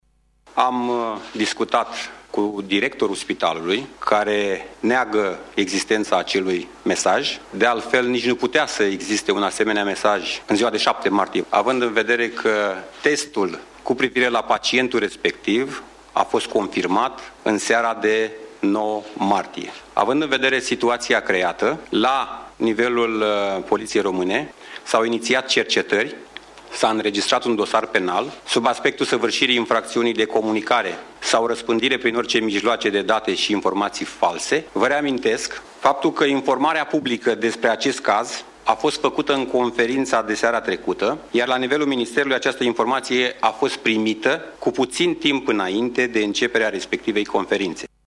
Tot astăzi, secretarul de stat în MAI Bogdan Despescu a făcut precizări la sediul ministerului referitoare la informațiile eronate apărute în cazul celui de-al 17 –lea pacient confirmat pozitiv: